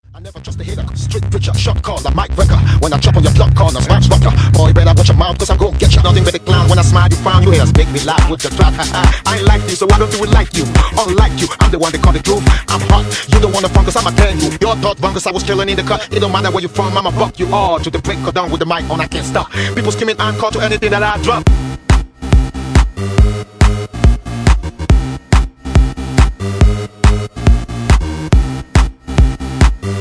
Absolutely mental tune from the UK.
Your looking at a POP chart number one.